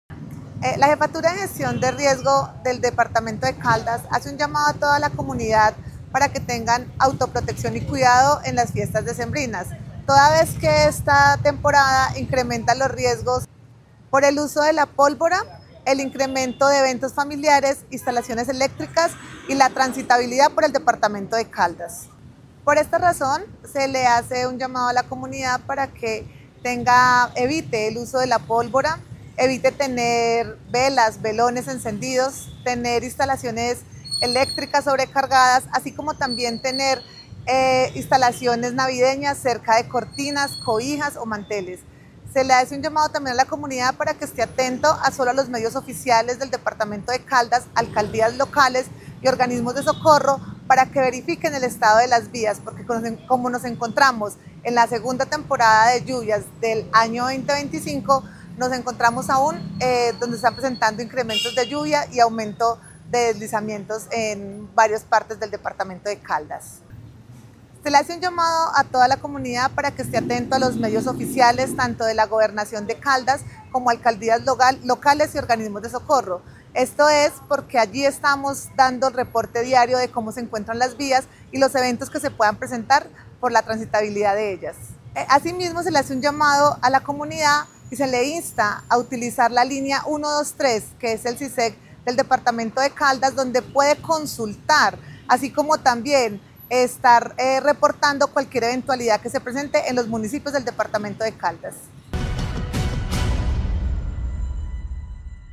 Paula Marcela Villamil Rendón, jefe de Gestión del Riesgo de Caldas.